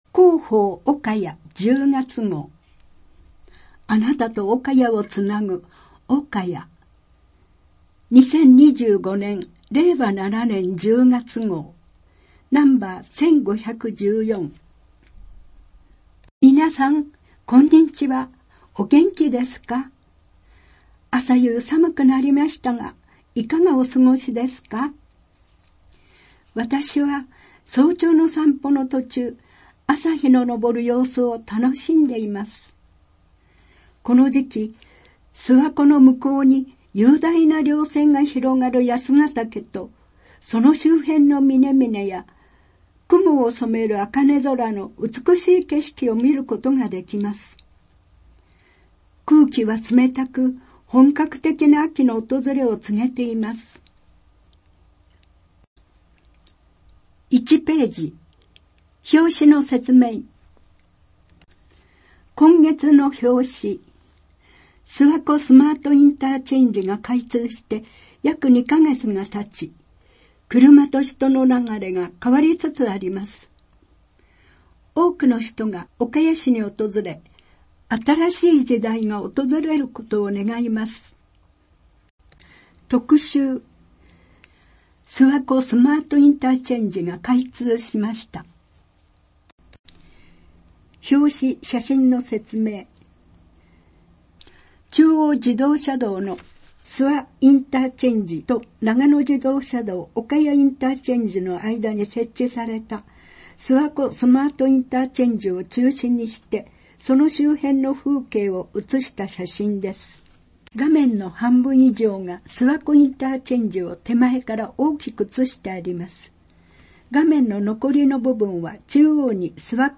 声の広報　おかや　音声版
声の広報は朗読の会まどかが担当しています。